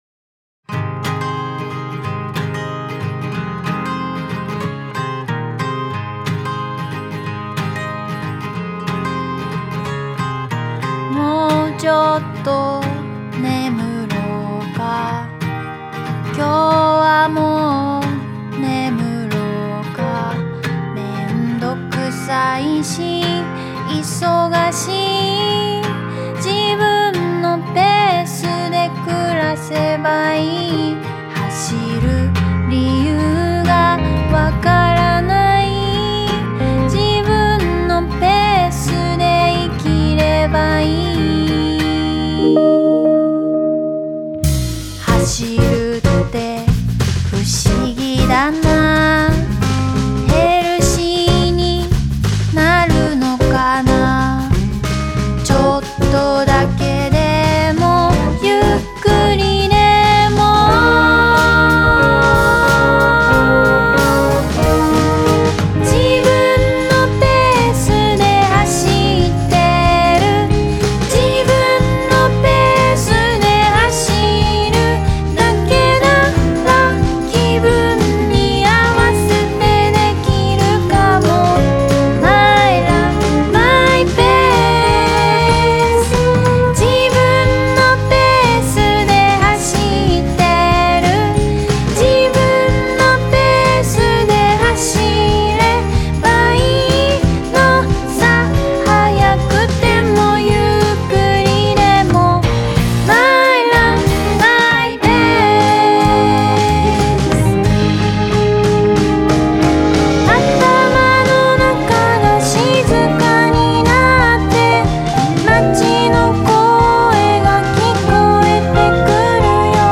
SONG
ROCK / POPS
Vocal
Chorus
E.Guitar
A.Guitar, Bass
Drumus